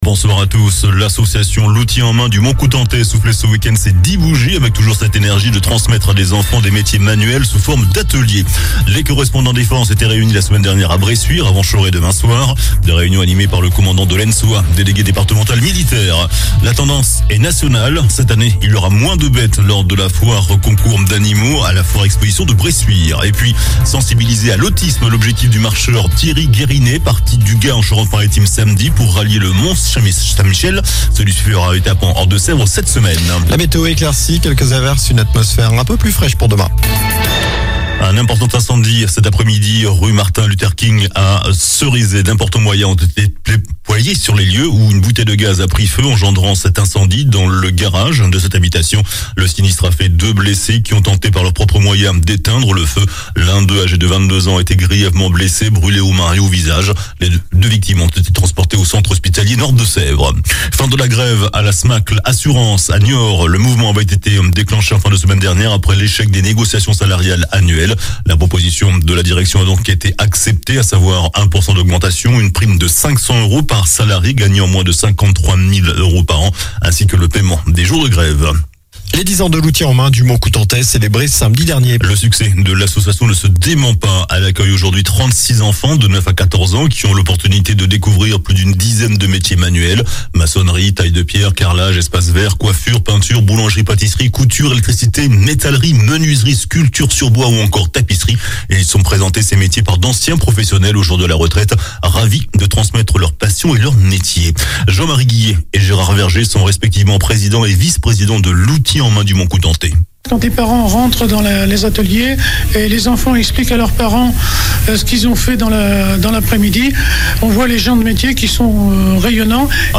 JOURNAL DU LUNDI 13 MARS ( SOIR )